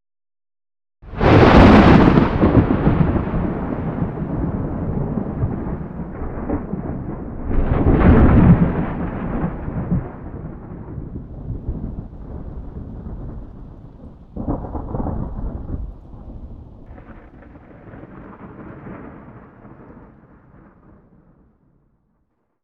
new_thunder3_hec.ogg